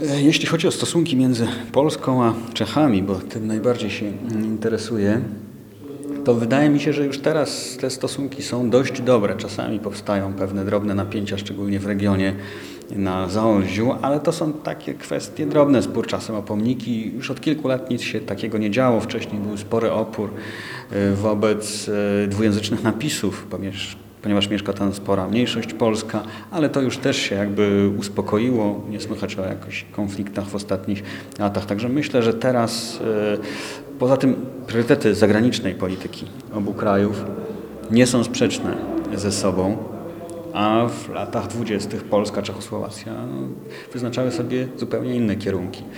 Kolejny wykład w muzeum
wykład-1.mp3